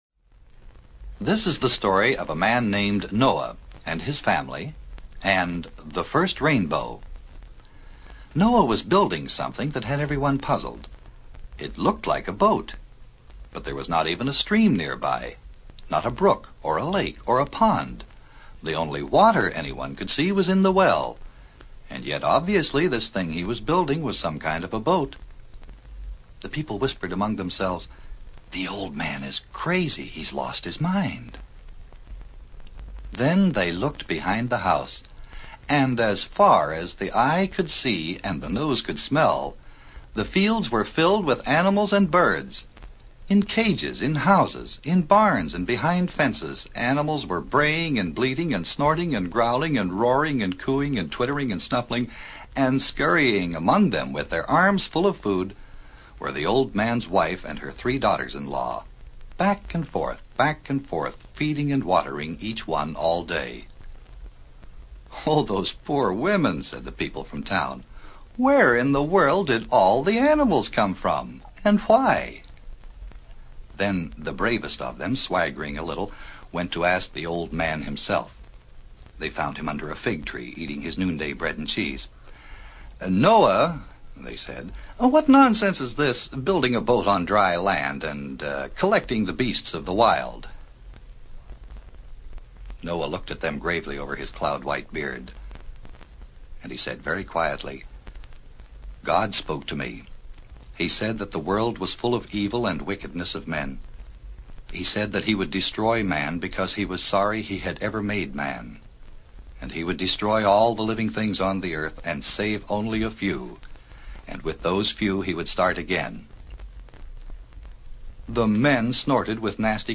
Bible Stories for Children